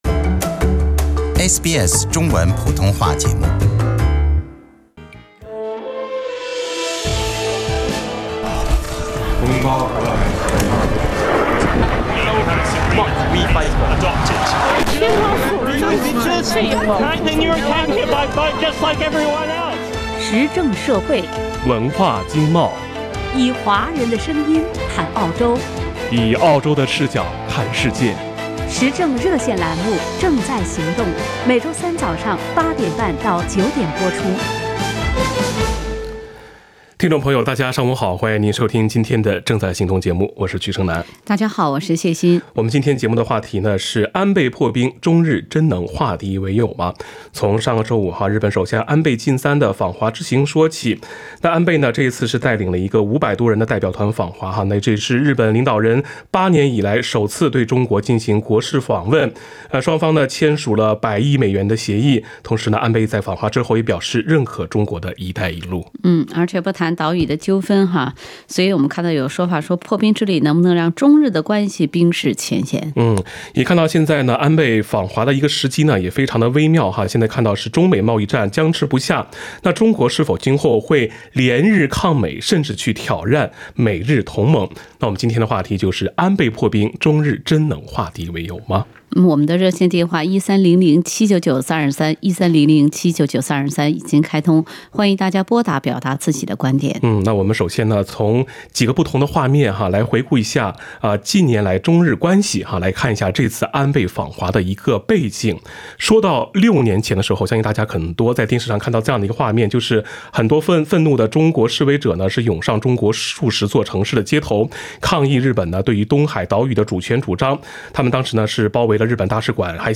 以上为听众言论节选，不代表本台立场。 时政热线节目《正在行动》逢周三上午8点30分至9点播出。